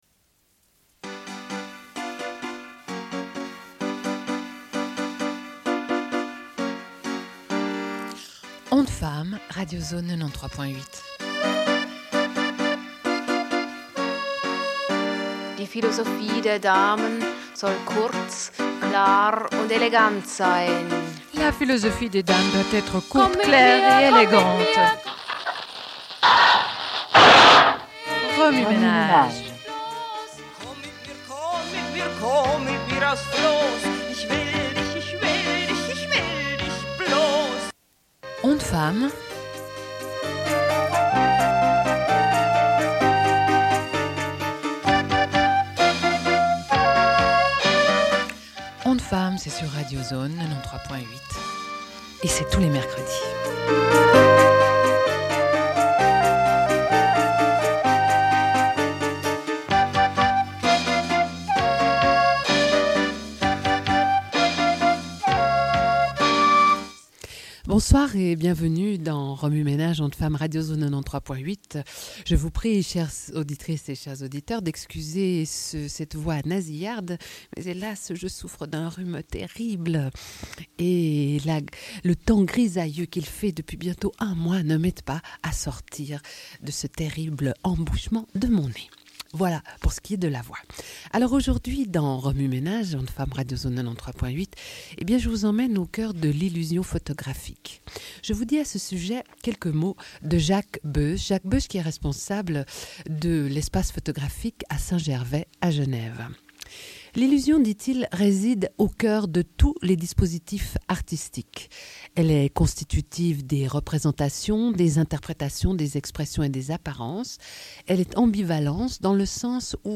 Sommaire de l'émission : au sujet d'une exposition de photographie à L'Espace photographique à Saint-Gervais, sur le Kurdistan et Cuba. Avec les interventions téléphoniques de deux femmes photographes
Radio